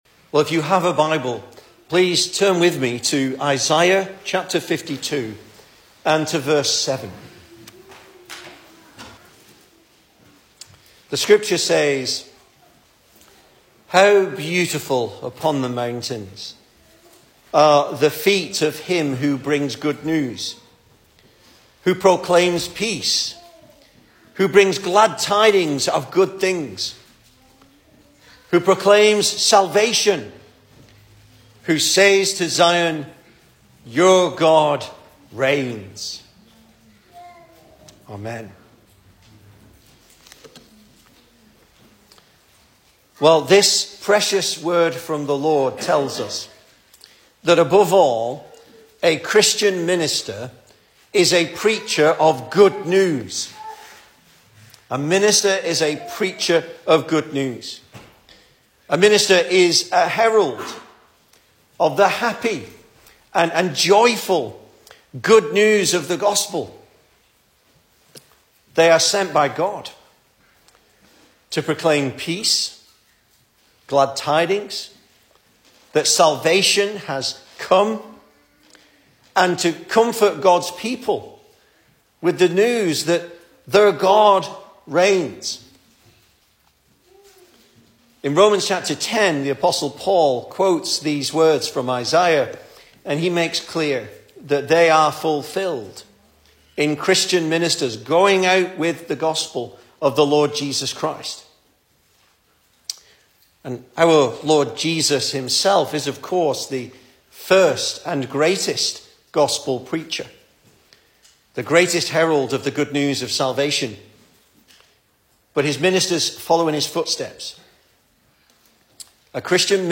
Service Type: Special Service
Series: Single Sermons